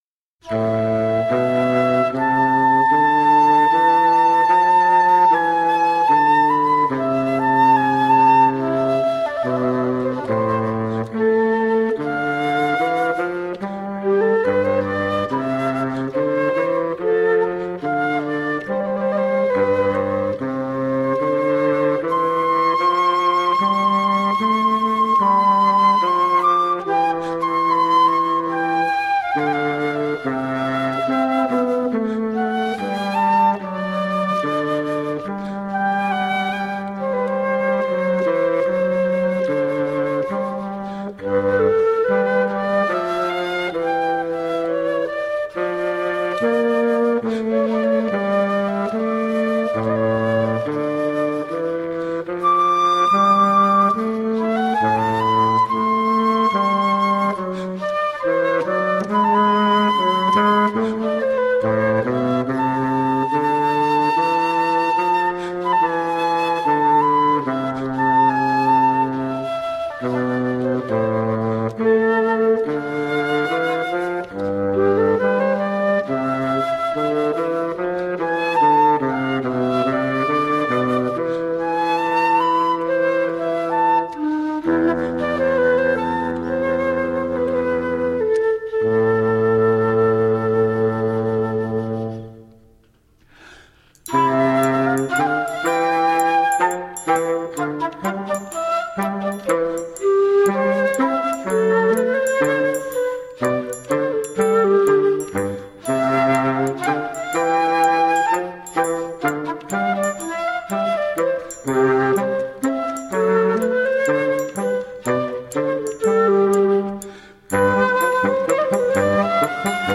Brazilian contemporary and traditional flute.
with flute, acoustic guitar, fagot and percussion